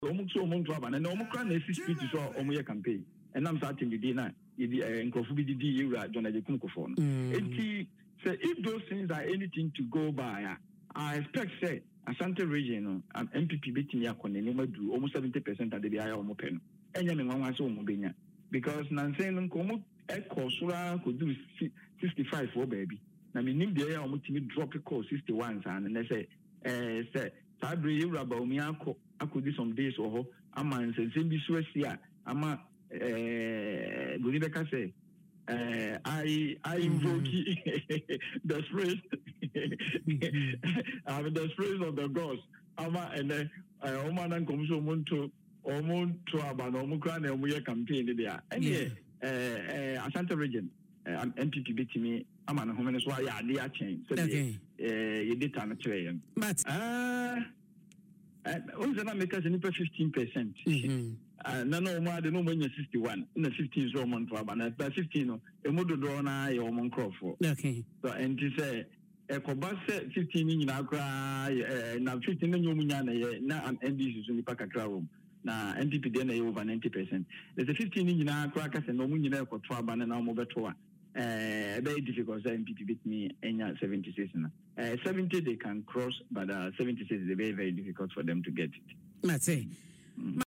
made the remarks on Adom FM’s morning show Dwaso Nsem Friday